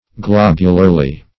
globularly - definition of globularly - synonyms, pronunciation, spelling from Free Dictionary Search Result for " globularly" : The Collaborative International Dictionary of English v.0.48: Globularly \Glob"u*lar*ly\, adv.
globularly.mp3